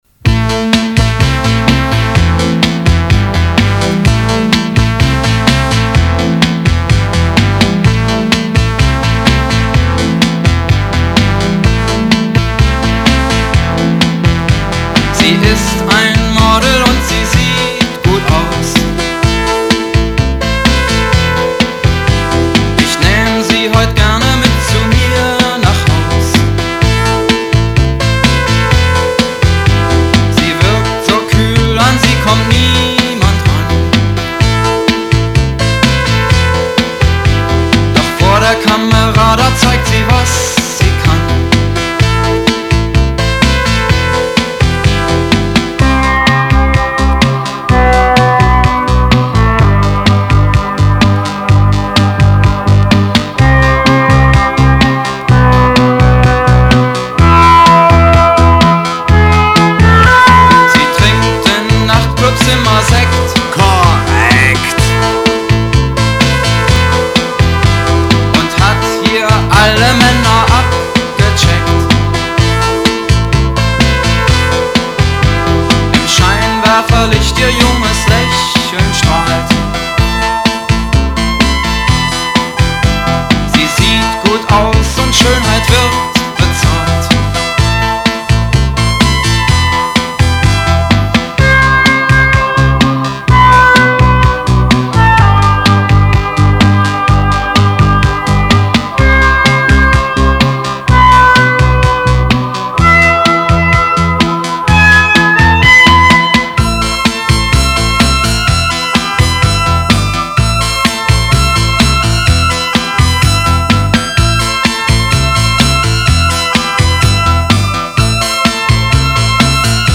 Electronic, Synth-Pop, Techno, Experimental